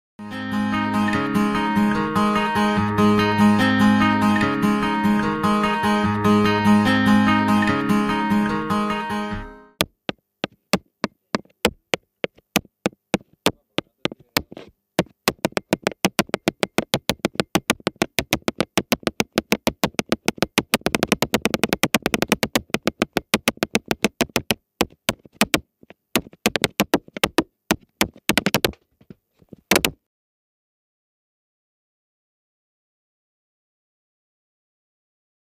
Zapatear_Estudio.mp3